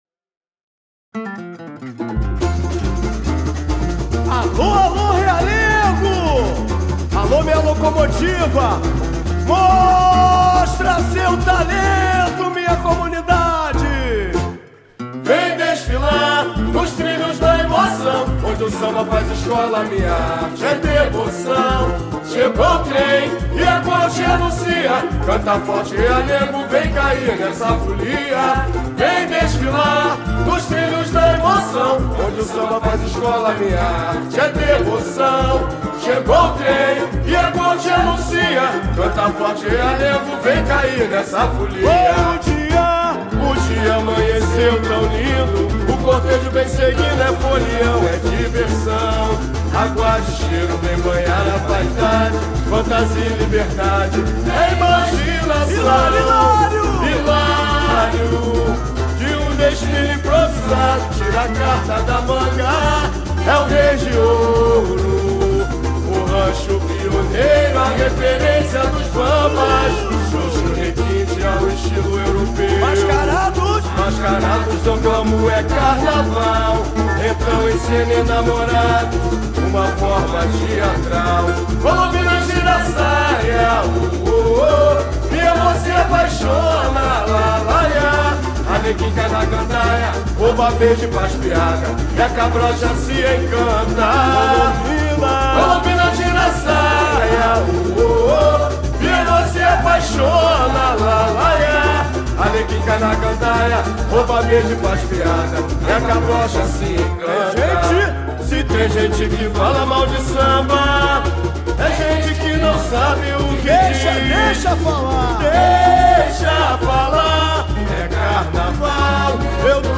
Samba-Enredo: Definido